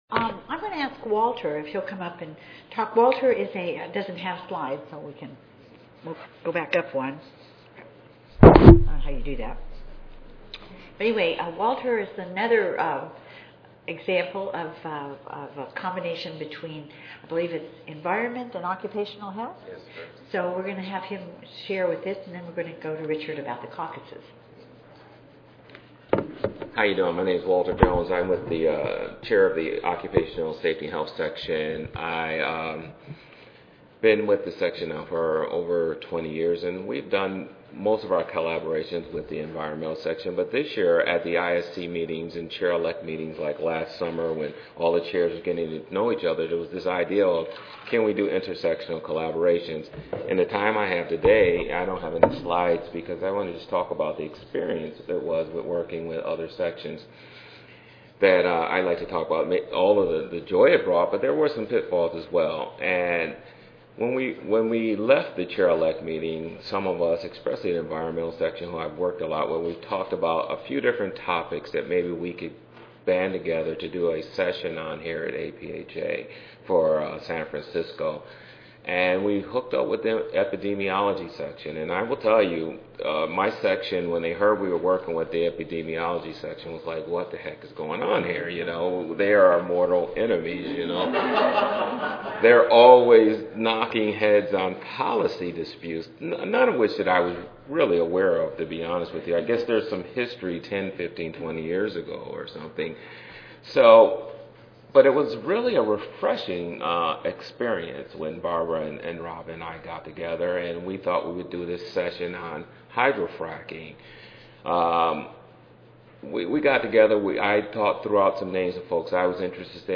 The proposed session will involve a panel of presenters who will discuss the potential impact of individual sections working together to set policy agencies and follow through with appropriate advocacy plans.
The session includes audience participation and response as follow up programming will be discussed.